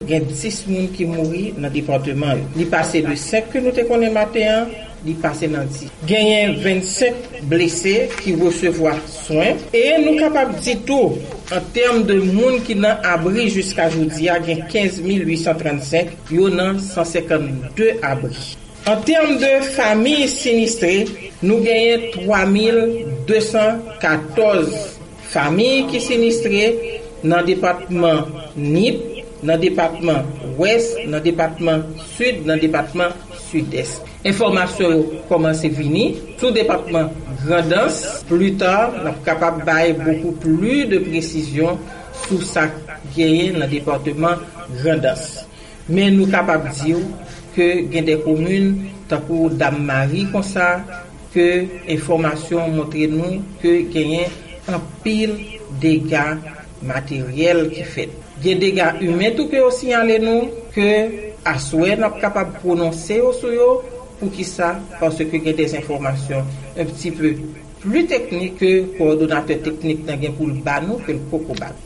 Direktris Pwoteksyon Sivil la, Alta Jean Baptiste, Prezante yon Bilan sou Dega Pasaj Siklòn Matthew nan Peyi Dayiti